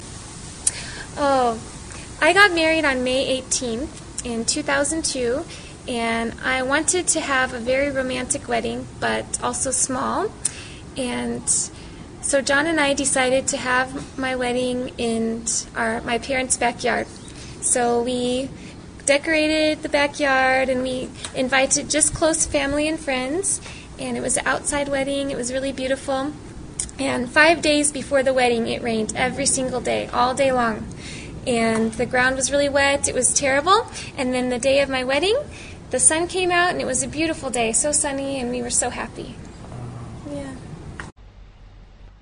英语高级口语对话正常语速03：婚礼——她的故事